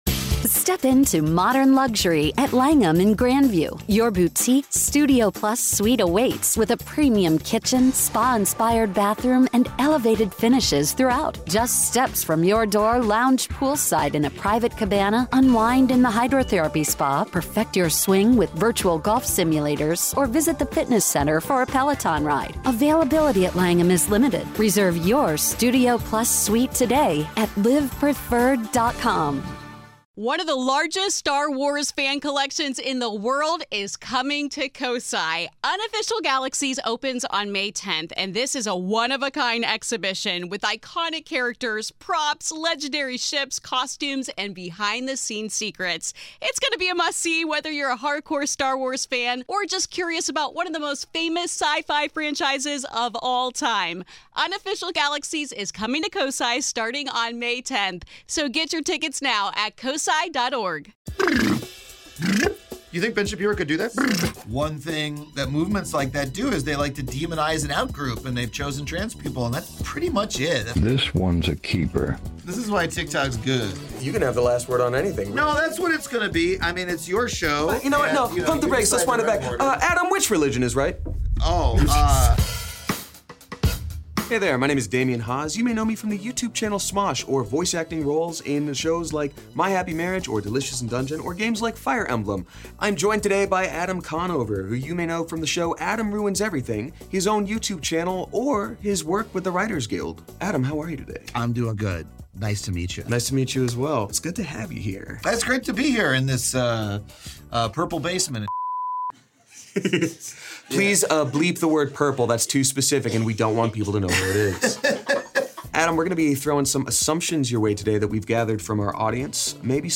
Adam Ruins This Interview